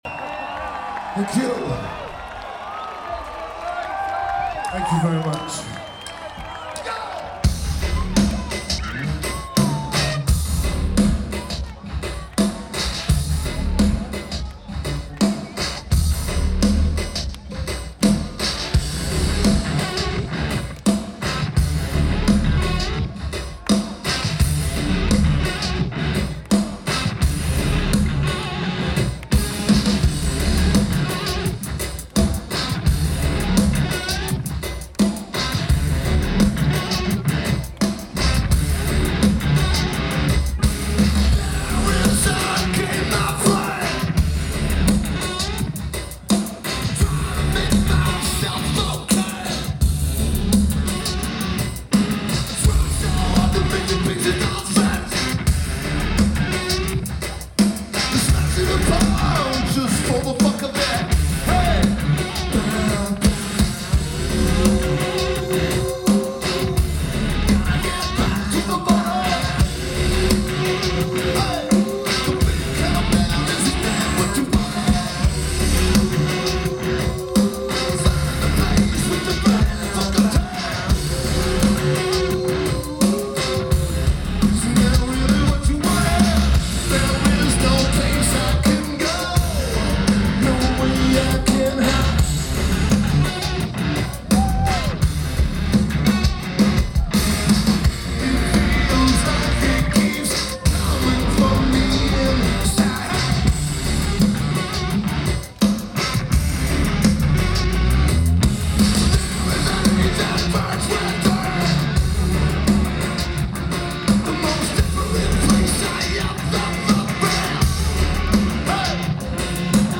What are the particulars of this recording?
Oxegen Festival Lineage: Audio - AUD (Zoom H4)